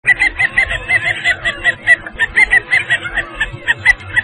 Le Fuligule Morillon (Mâle et femelle)